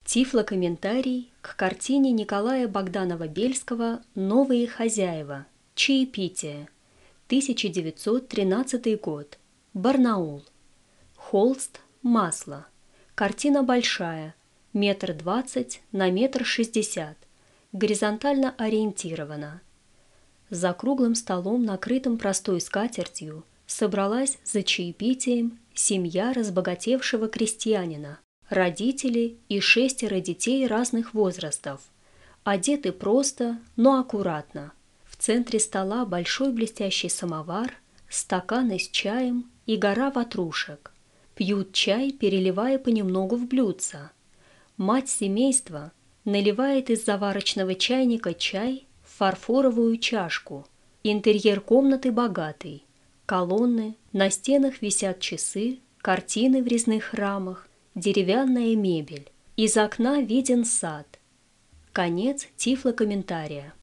Тифлокомментарий к картине Николая Богданова-Бельского «Новые хозяева. Чаепитие», 1913 год, Барнаул.